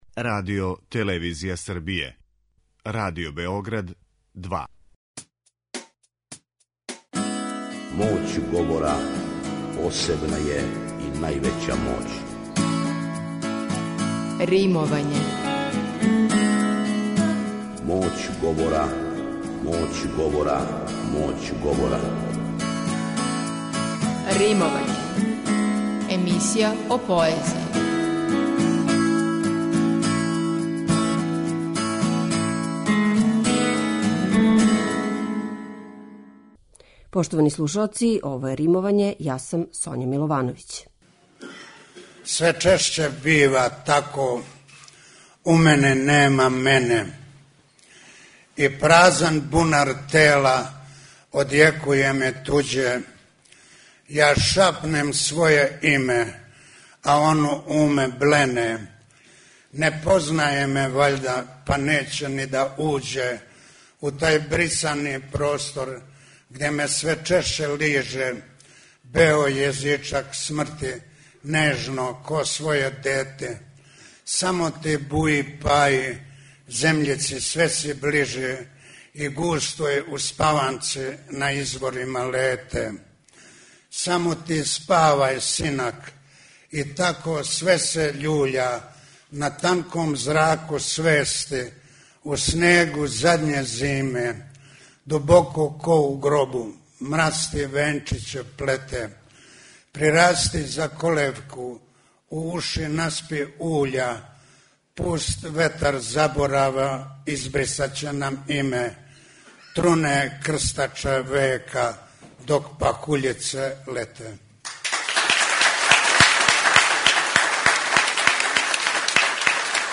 У Римовању , слушате део снимка поетске вечери Рајка Петрова Нога одржане на Коларцу у оквиру програмског циклуса Радио Београда 2 "Поезија уживо".